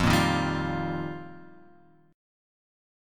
F7sus2sus4 chord {1 1 1 0 x 1} chord